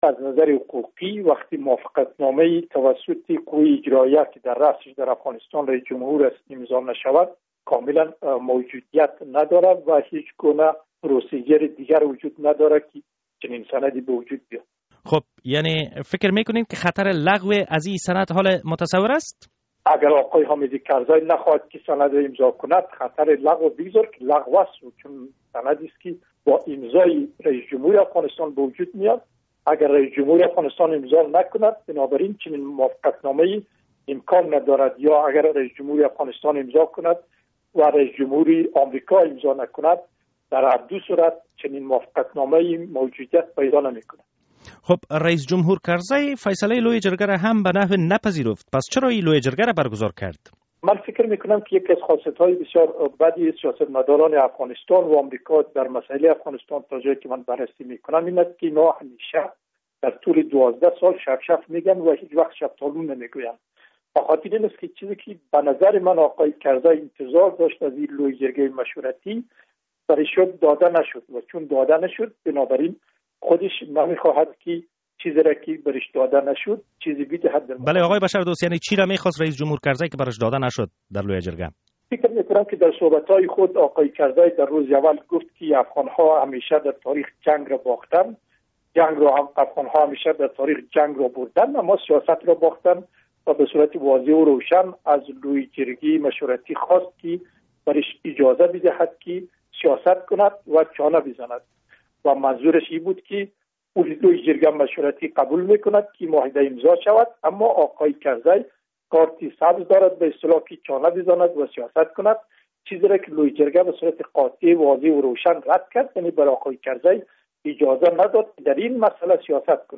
مصاحبه: رد شدن خواست اخیر امریکا، چی مفهومی دارد؟